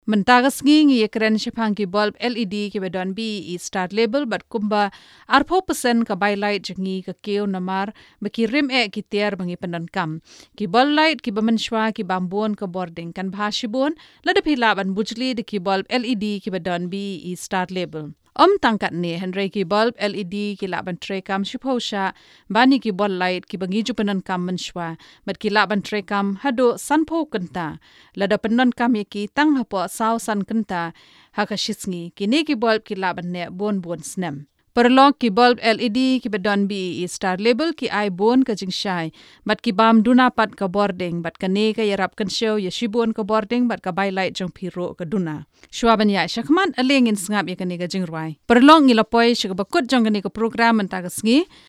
Female Khasi
Professional Khasi female .........
Khasi Female ........